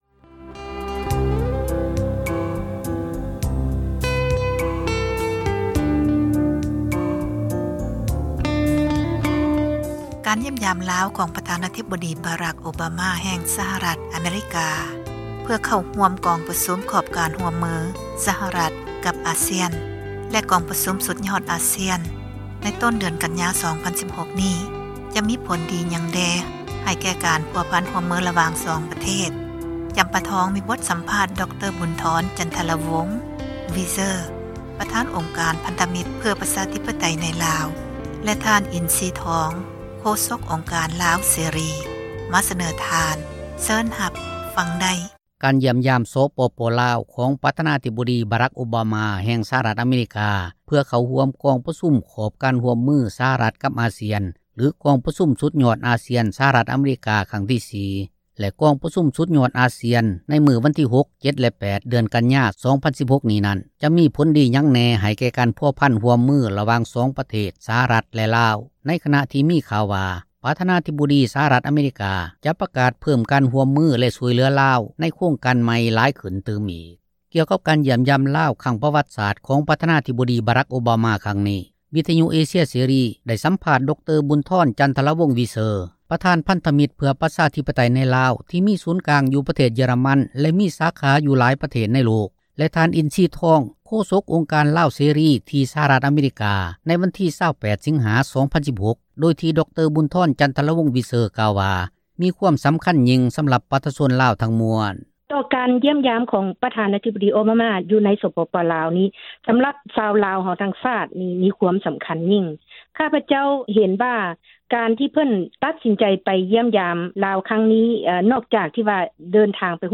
ສັມພາດນັກເຄື່ອນໄຫວ